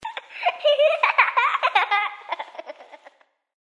cackle_21310.mp3